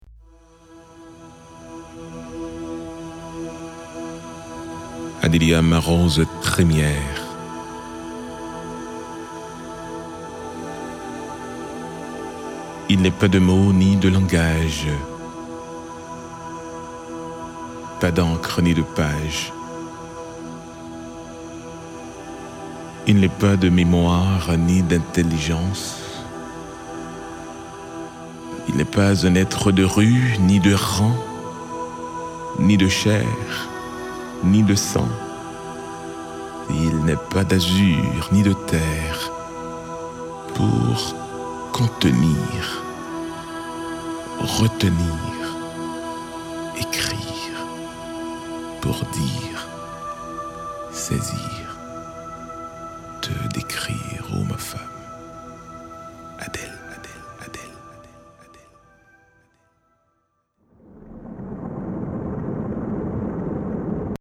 Creusant la même veine d’émotion, le comédien Maka Kotto porte avec combien de justesse et d’élégance le souffle du messager .
Lettre à Adélia, un livre audio inspirant et touchant aux couleurs de cette terre chaleureuse et tant aimée qu’est Haïti.